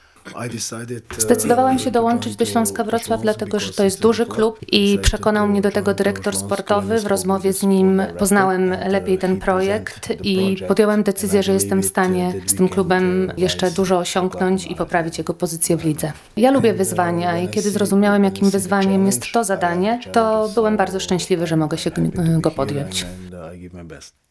Słoweniec na pierwszej konferencji prasowej przyznał, że nie jest magikiem, ale wierzy w osiągniecie wyznaczonego mu celu. Dlaczego zdecydował się podjąć tego arcytrudnego zadania?